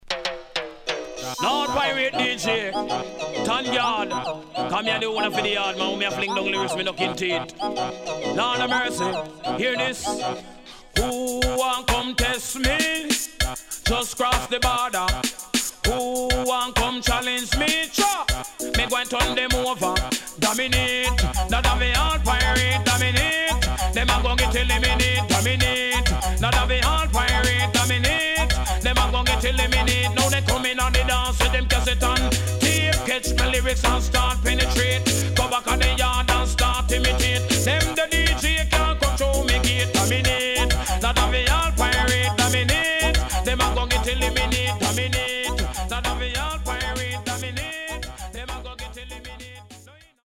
HOME > Back Order [DANCEHALL DISCO45]
SIDE A:少しノイズ入りますが良好です。